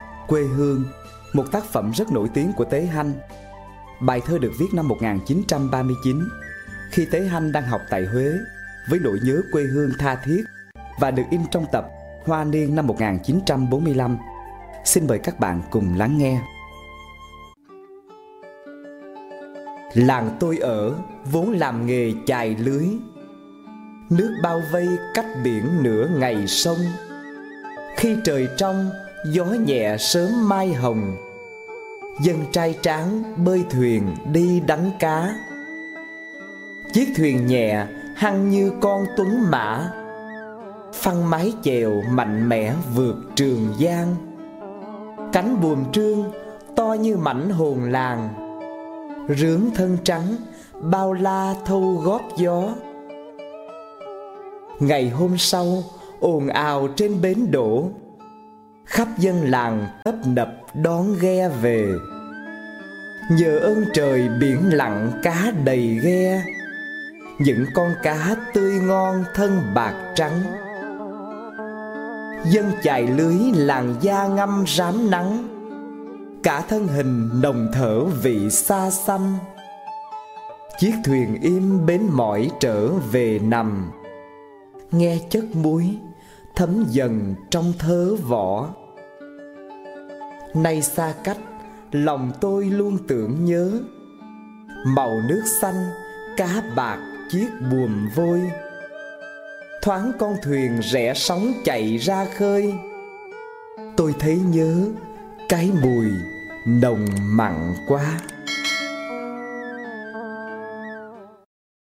Sách nói | Ngữ văn 7 - Kết nối | Quê hương